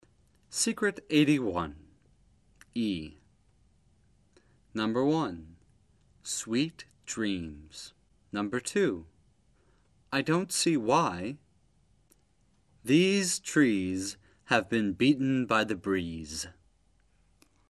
李阳美语发音秘诀MP3之秘诀81:前元音［i］的发音技巧 听力文件下载—在线英语听力室